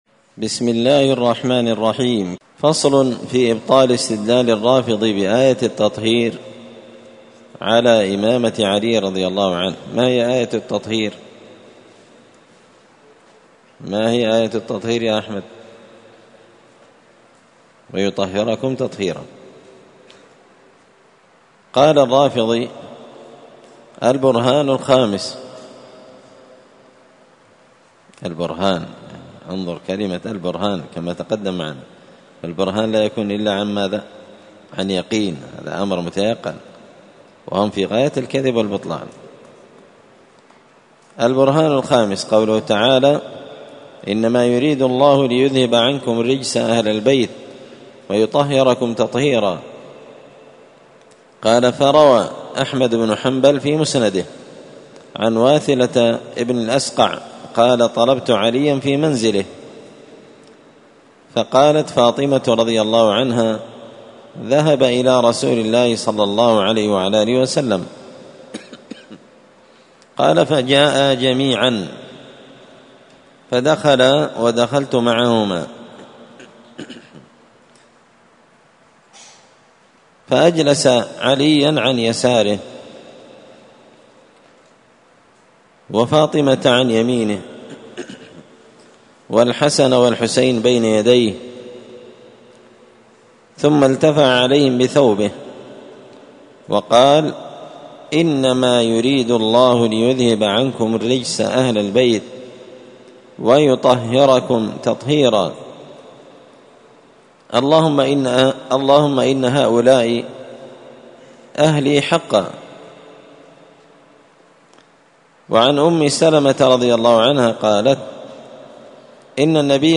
الأربعاء 29 محرم 1445 هــــ | الدروس، دروس الردود، مختصر منهاج السنة النبوية لشيخ الإسلام ابن تيمية | شارك بتعليقك | 101 المشاهدات